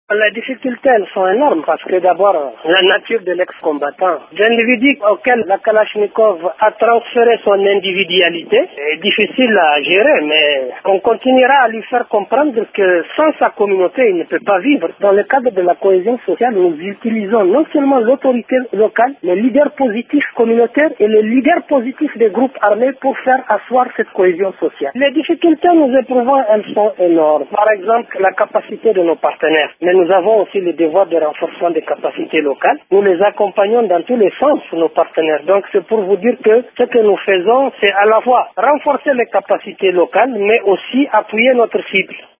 Il l’explique dans cet extrait recueilli par Radio Okapi: